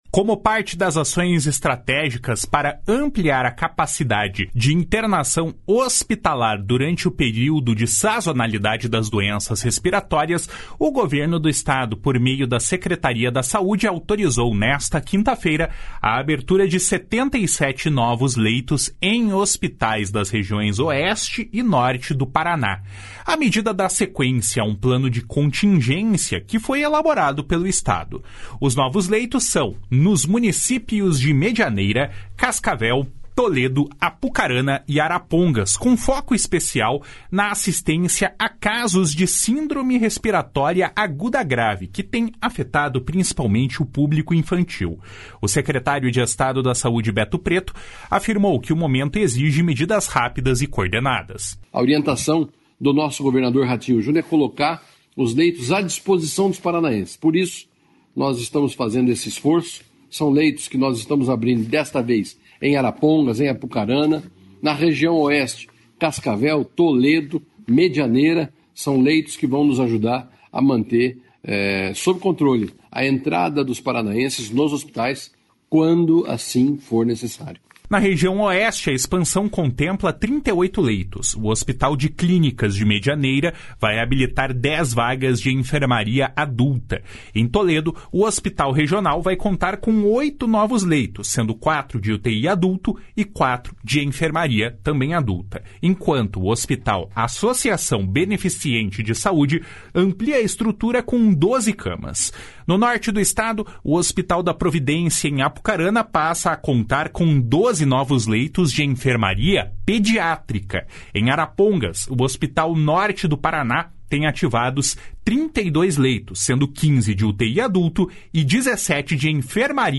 Os novos leitos são nos municípios de Medianeira, Cascavel, Toledo, Apucarana e Arapongas, com foco especial na assistência a casos de Síndrome Respiratória Aguda Grave, que têm afetado principalmente o público infantil. O secretário de Estado da Saúde, Beto Preto, afirmou que o momento exige medidas rápidas e coordenadas. // SONORA BETO PRETO //